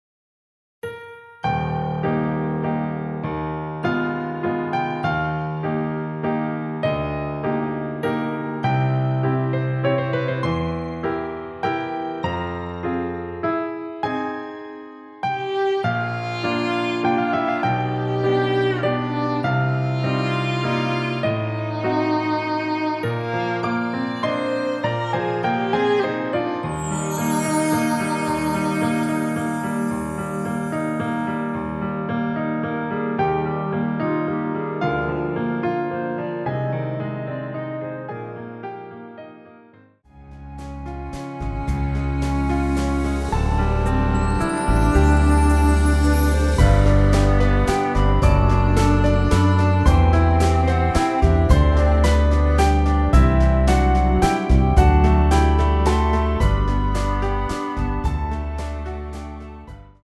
원키에서(-3)내린 멜로디 포함된 MR입니다.
Eb
앞부분30초, 뒷부분30초씩 편집해서 올려 드리고 있습니다.
중간에 음이 끈어지고 다시 나오는 이유는